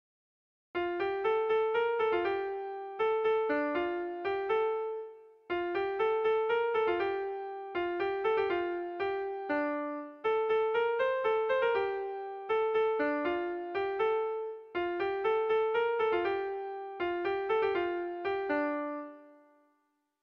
Erlijiozkoa
Zortziko txikia (hg) / Lau puntuko txikia (ip)
ABDB